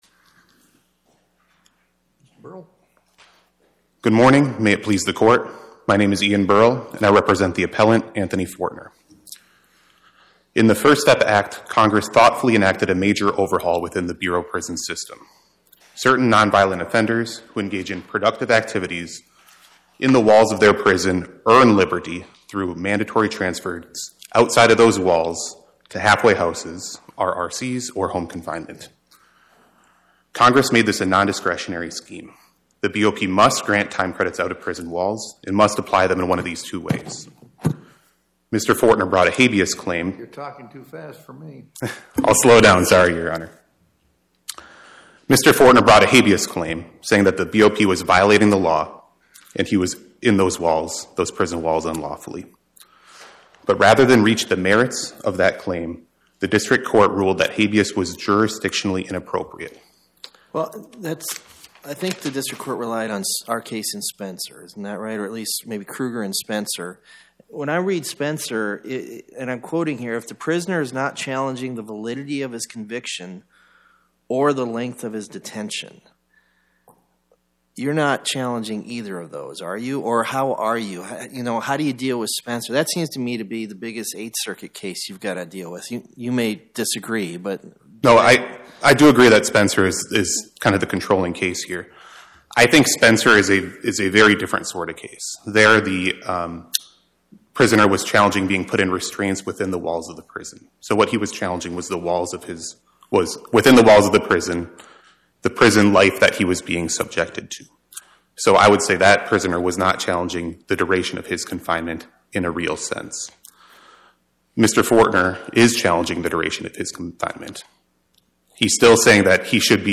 Oral argument argued before the Eighth Circuit U.S. Court of Appeals on or about 12/17/2025